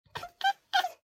assets / minecraft / sounds / mob / cat / beg2.ogg
beg2.ogg